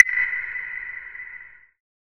soft-hitclap.ogg